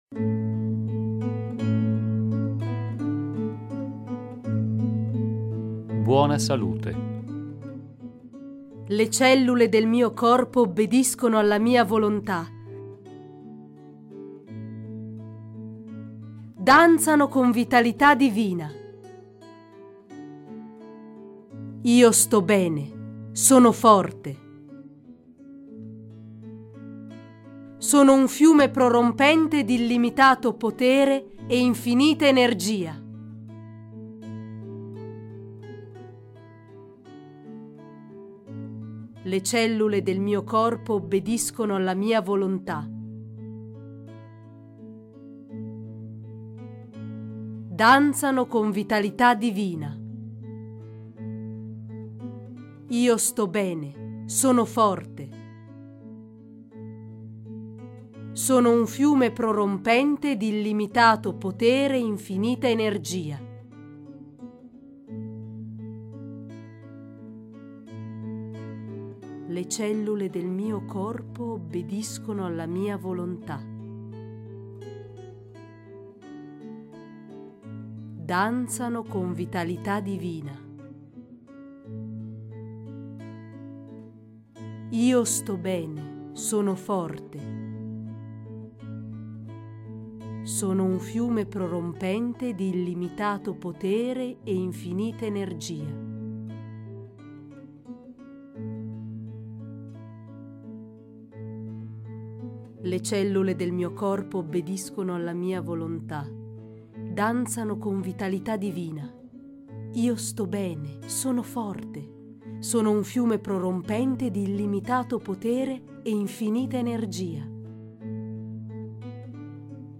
Le affermazioni per l’autoguarigion hanno successo là dove altri metodi falliscono, perché il loro linguaggio facilmente comprensibile, rafforzato dalla ripetizione vocale, innesca un processo di profonda guarigione interiore.